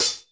Boom-Bap Hat CL 81.wav